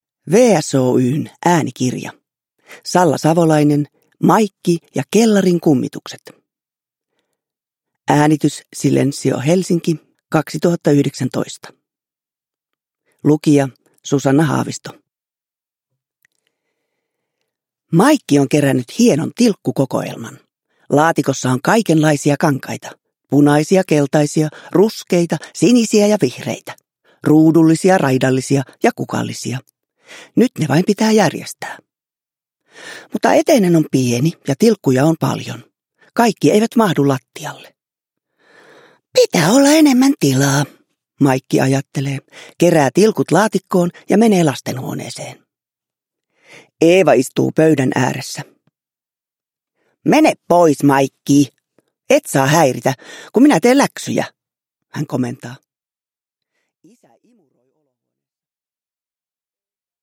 Maikki ja kellarin kummitukset – Ljudbok – Laddas ner
Uppläsare: Susanna Haavisto